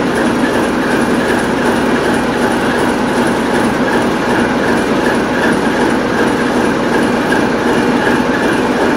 CHME3_Disel.ogg